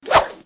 flycutting.mp3